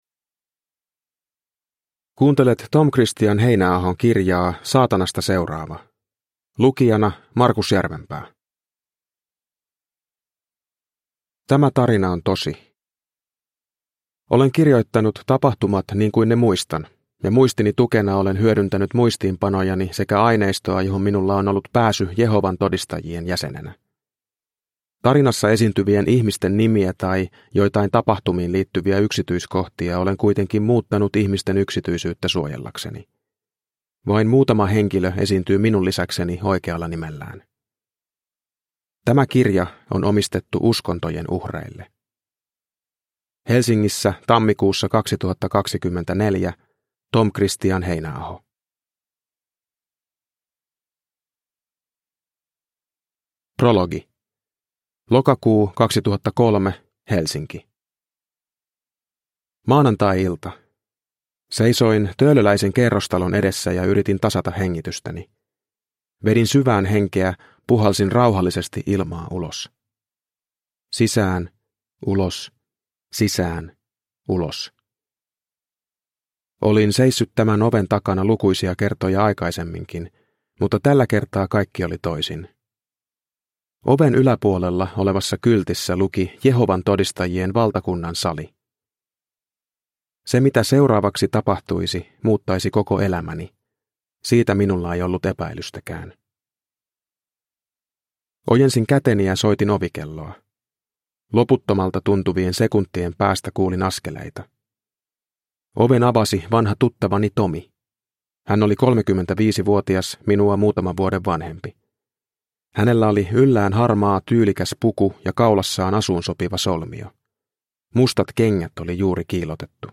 Saatanasta seuraava – Ljudbok